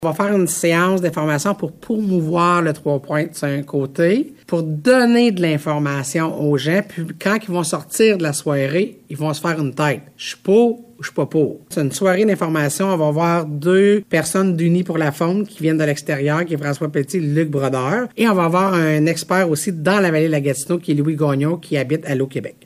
Le comité Faune de la MRC Vallée-de-la-Gatineau (MRCVG) présente une soirée d’information et de sensibilisation qui s’adresse à tous les chasseurs de la région. Les gens sur place recevront de l’information sur l’initiative de récolter des cerfs possédant au minimum 3 pointes sur un côté du panache. La préfète de la MRC Vallée-de-la-Gatineau, Chantal Lamarche, explique :